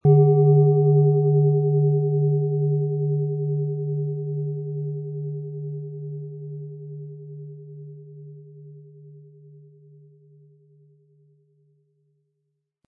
Planetenschale® Mutig sein & Angeregt fühlen mit Mars, Ø 18,3 cm, 700-800 Gramm inkl. Klöppel
Planetenton 1
Im Sound-Player - Jetzt reinhören können Sie den Original-Ton genau dieser Schale anhören.
Der kräftige Klang und die außergewöhnliche Klangschwingung der traditionellen Herstellung würden uns jedoch fehlen.
SchalenformBihar
MaterialBronze